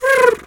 pgs/Assets/Audio/Animal_Impersonations/pigeon_2_emote_03.wav at master
pigeon_2_emote_03.wav